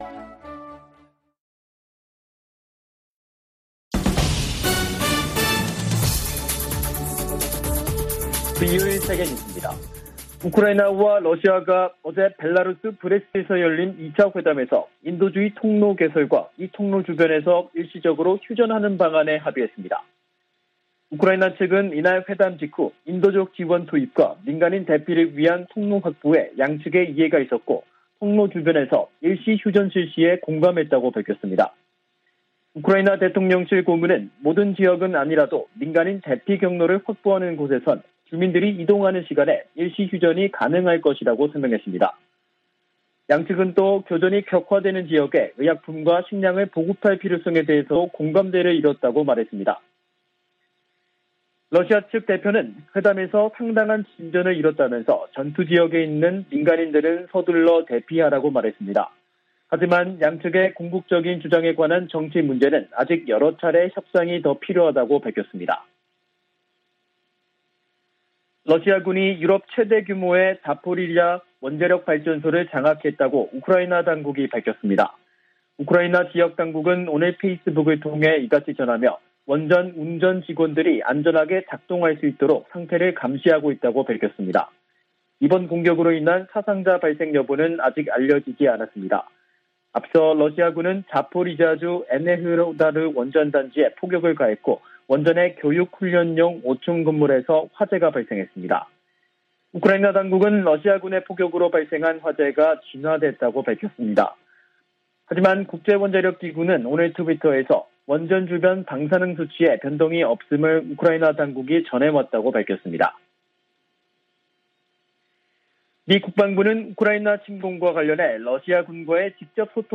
VOA 한국어 간판 뉴스 프로그램 '뉴스 투데이', 2022년 3월 4일 3부 방송입니다. 미 국무부는 러시아가 한국의 제재 동참에 유감을 표명한 데 대해, 국제사회가 무의미한 전쟁을 방어하는데 단결하고 있다고 강조했습니다. 북한이 우크라이나 사태에 러시아를 적극 두둔한 것은 핵 보유국 지위 확보를 노린 것이라는 분석이 나오고 있습니다. 미 상원의원들이 북한 등을 암호화폐 악용 국가로 지목하고 대책 마련을 촉구했습니다.